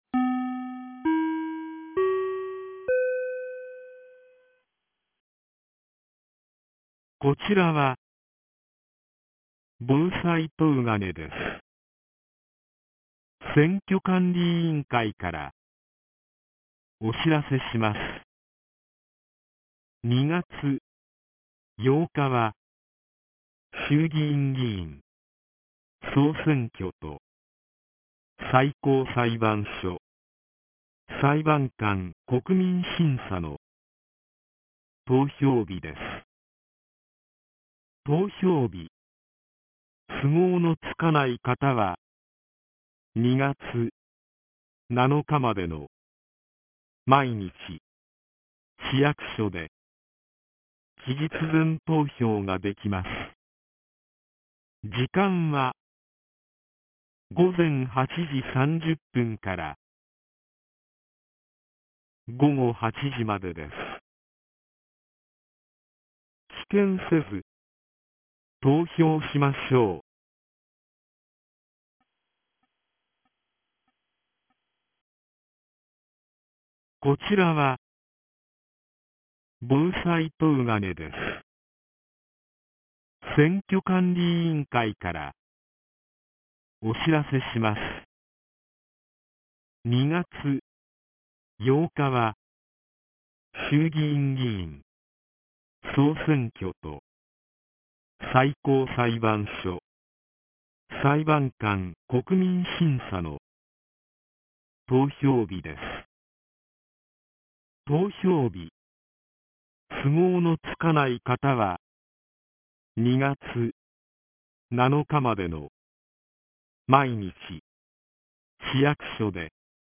2026年01月28日 14時02分に、東金市より防災行政無線の放送を行いました。